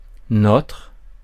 Ääntäminen
France: IPA: [nɔtʁ]